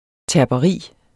Udtale [ tæɐ̯bʌˈʁiˀ ]